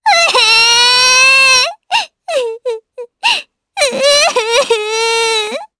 Estelle-Vox_Sad_jp.wav